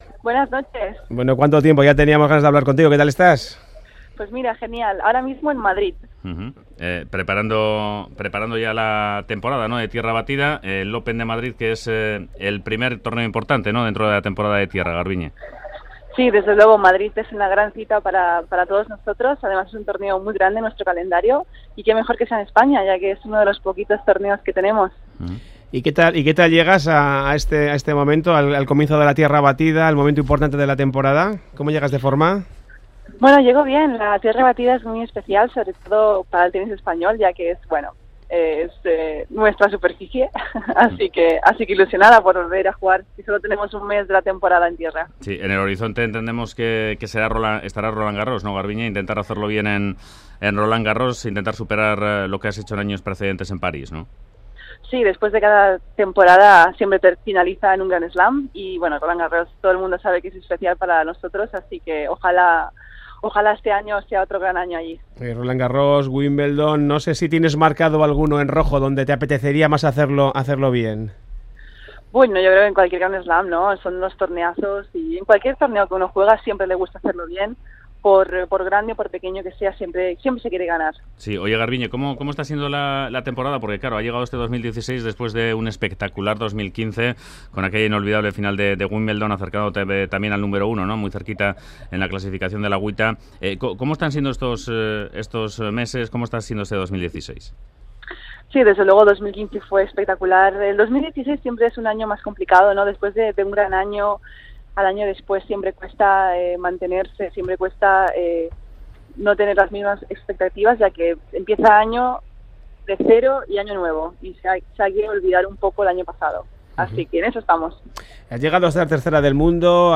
Entrevista a tenista Garbiñe Muguruza, a las puertas del Open Madrid | EITB Radio